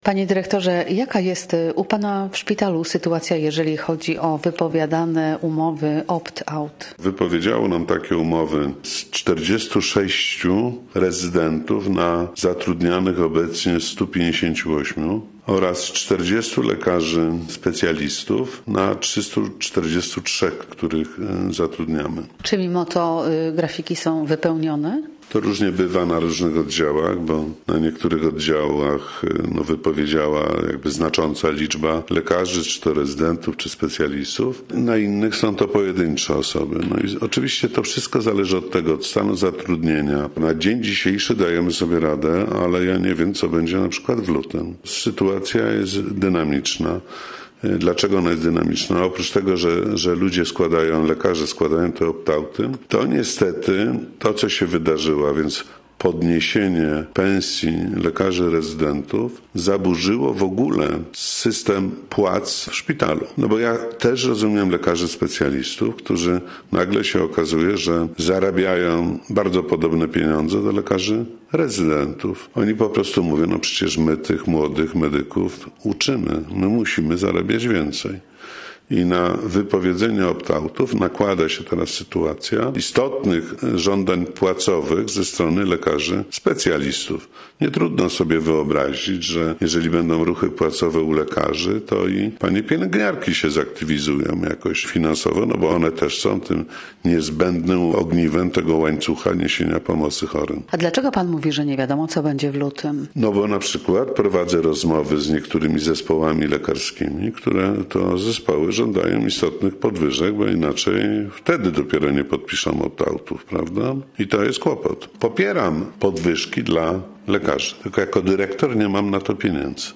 Nazwa Plik Autor Rozmowa z dyr.